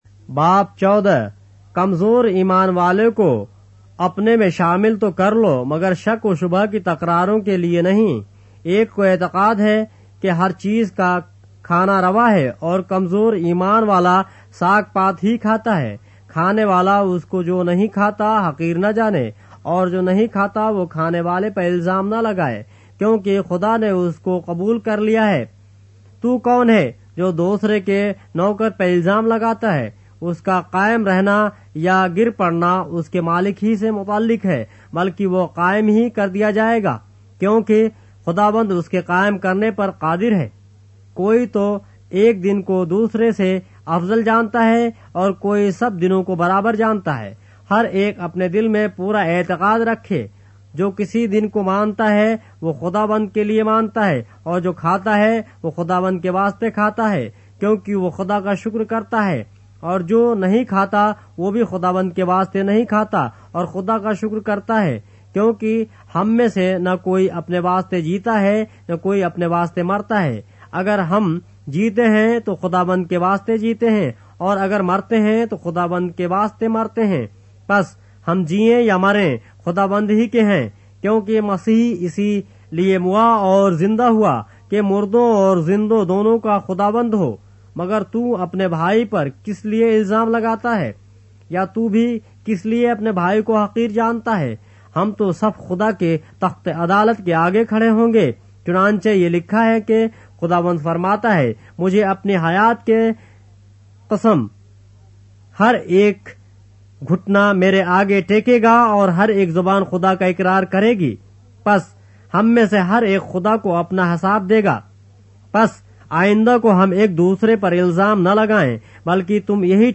اردو بائبل کے باب - آڈیو روایت کے ساتھ - Romans, chapter 14 of the Holy Bible in Urdu